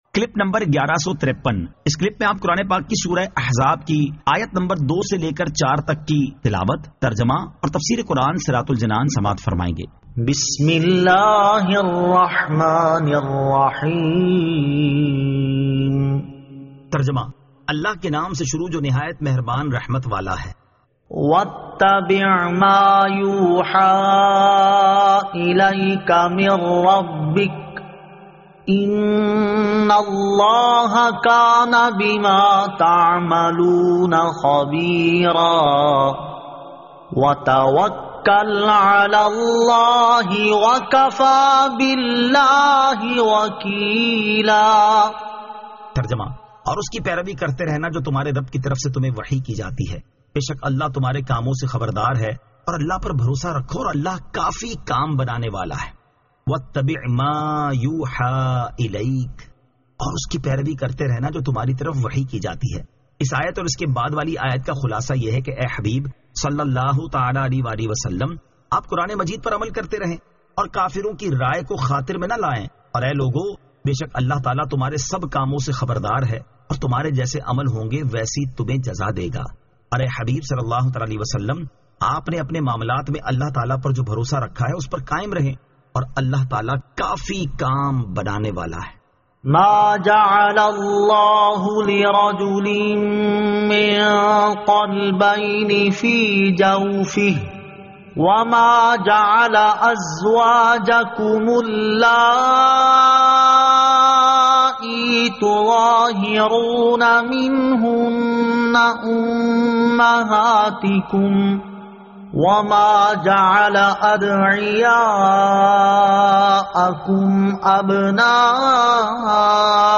Surah Al-Ahzab 02 To 04 Tilawat , Tarjama , Tafseer
2023 MP3 MP4 MP4 Share سُوَّرۃُ الٗاحٗزَاب آیت 02 تا 04 تلاوت ، ترجمہ ، تفسیر ۔